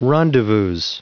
Prononciation du mot rendezvous en anglais (fichier audio)
Prononciation du mot : rendezvous